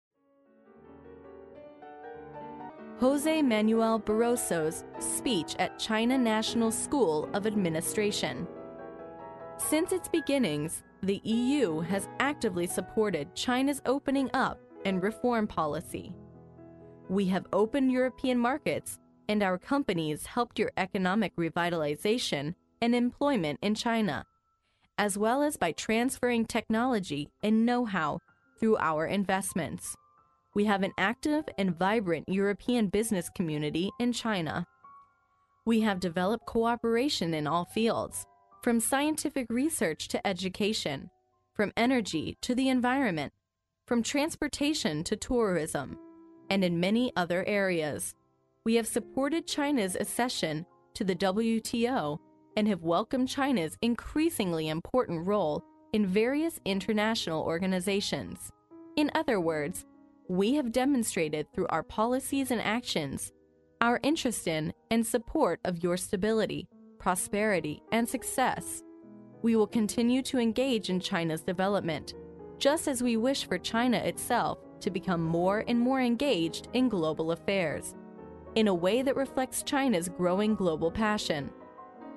历史英雄名人演讲 第45期:欧盟委员会主席巴罗佐在国家行政学院的演讲(1) 听力文件下载—在线英语听力室